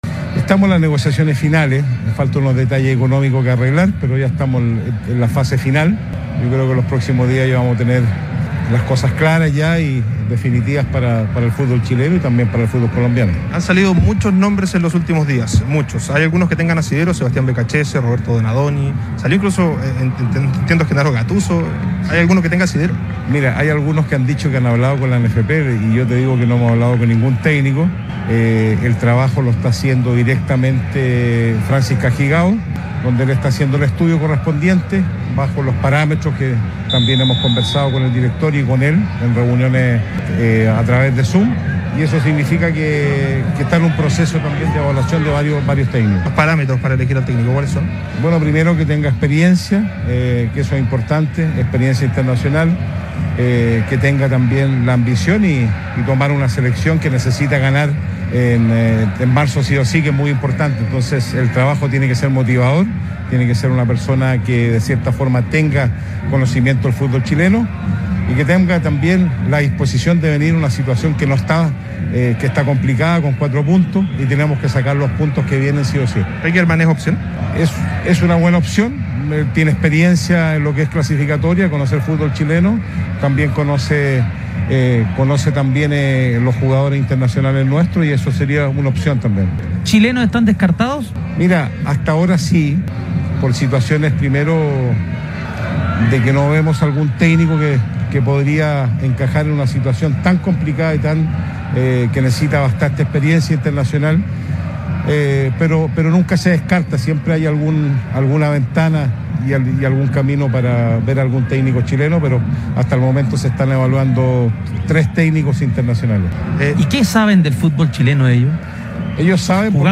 (Pablo Milad, presidente de la ANFP, en diálogo con ADN Radio)